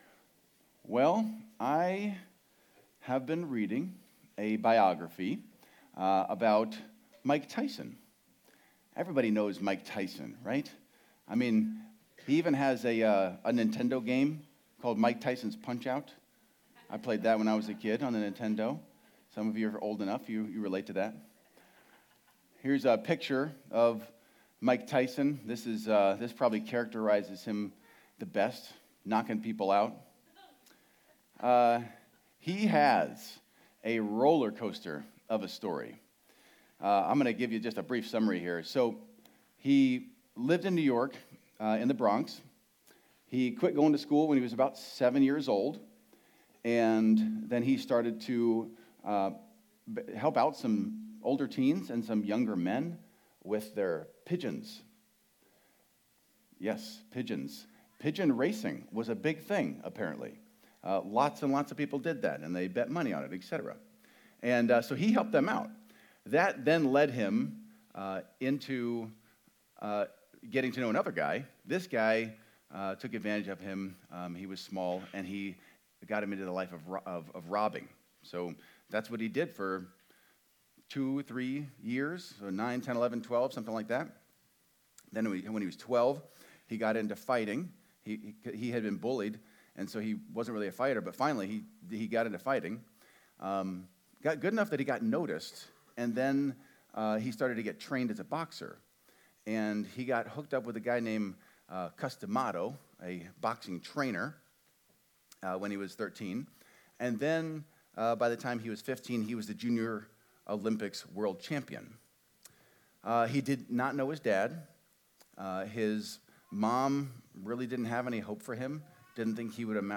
Video Audio Download Audio Home Resources Sermons Are you on God's Construction Crew?